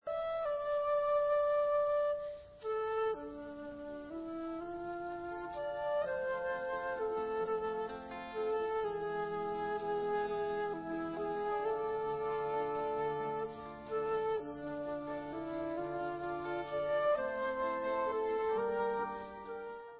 Recorded live november 1994